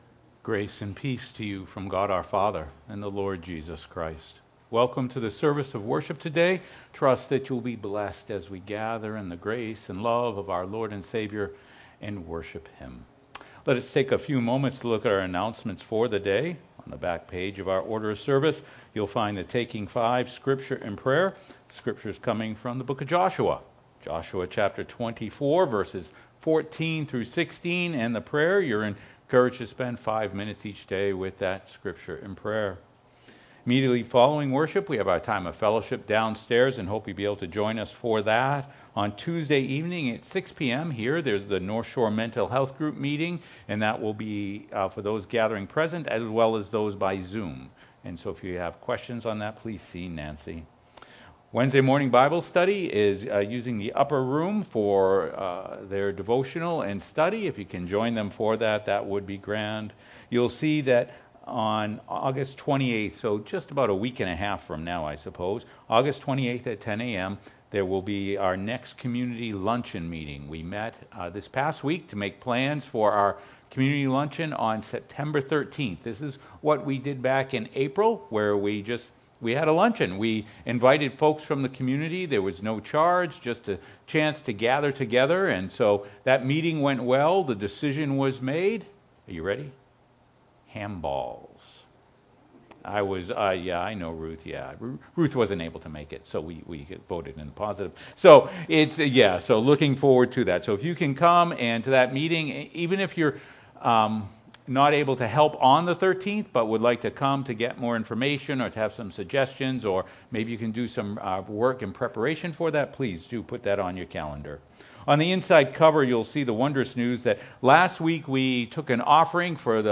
sermon-3.mp3